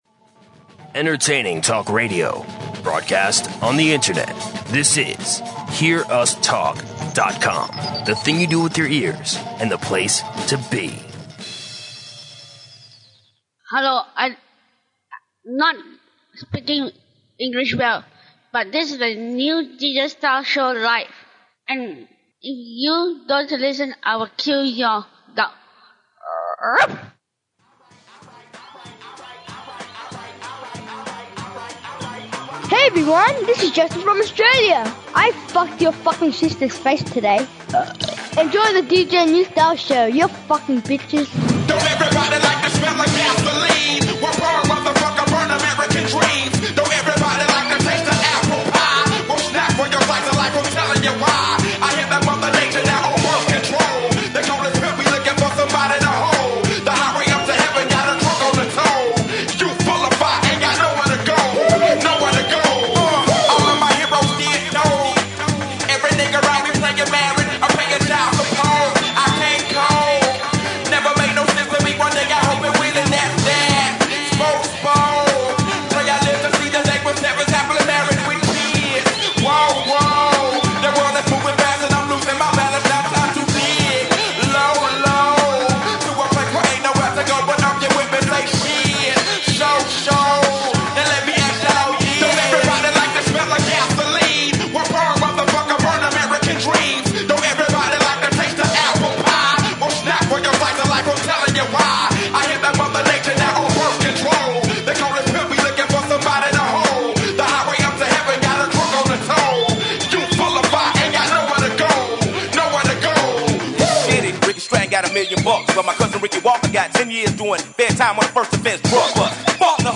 Tempers flare as technology breaks down! Listen for quizzes, stories, tales, and screaming.